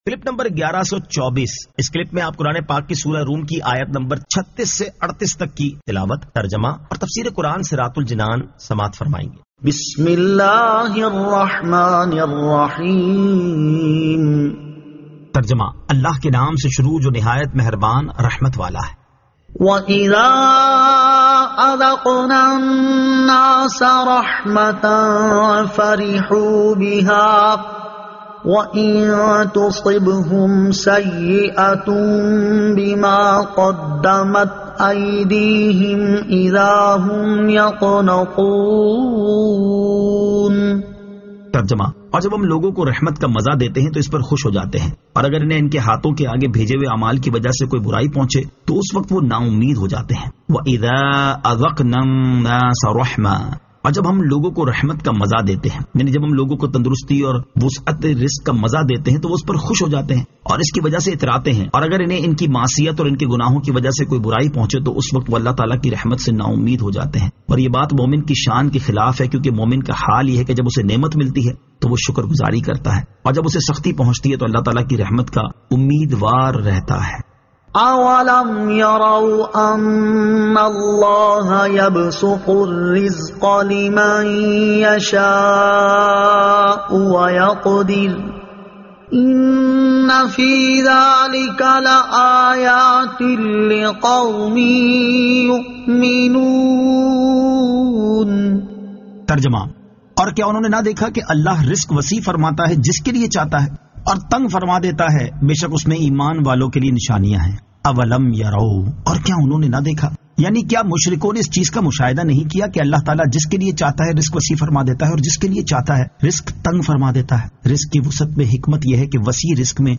Surah Ar-Rum 36 To 38 Tilawat , Tarjama , Tafseer